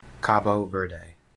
2. ^ /ˌkɑːb ˈvɜːrd/
KAH-boh VUR-day, /ˌkæb -/ KAB-oh -, local [ˈkabu ˈveɾdɨ]
En-Cabo_Verde-pronunciation.ogg.mp3